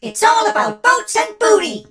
darryl_lead_vo_02.ogg